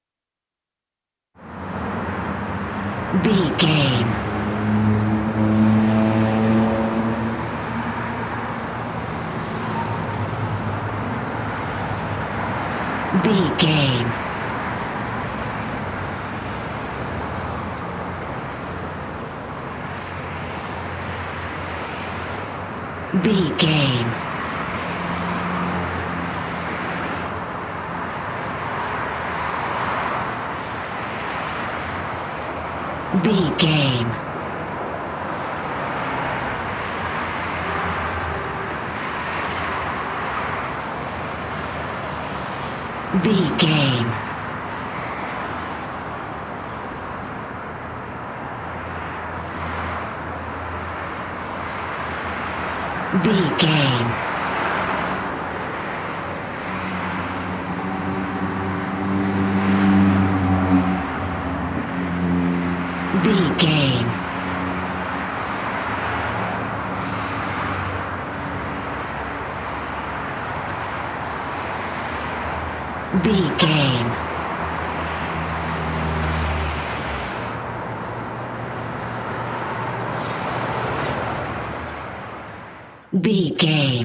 City avenue vehicles pass by 22
Sound Effects
urban
chaotic
ambience